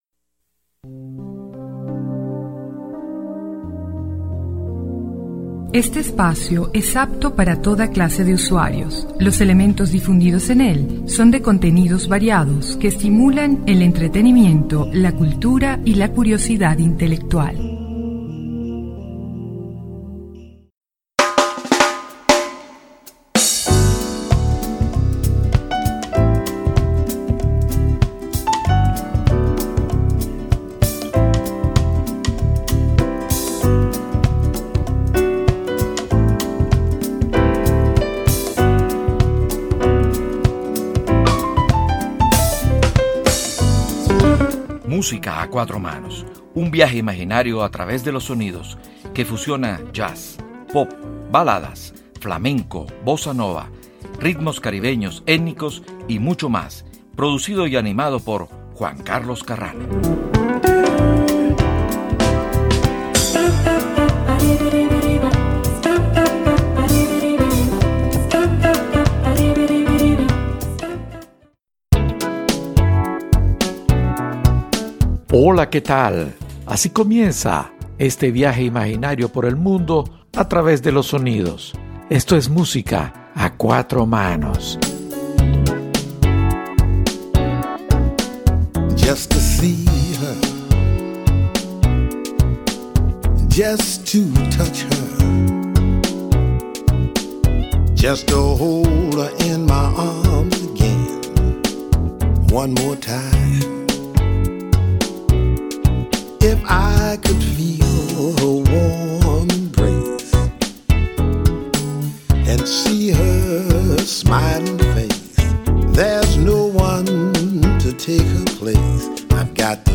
Pianista y cantante brasilera
con un torrente de voz y sensualidad unicos.